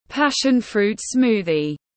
Sinh tố chanh leo tiếng anh gọi là passion fruit smoothie, phiên âm tiếng anh đọc là /ˈpæʃ.ən fru:t smuː.ði/
Passion fruit smoothie /ˈpæʃ.ən fru:t smuː.ði/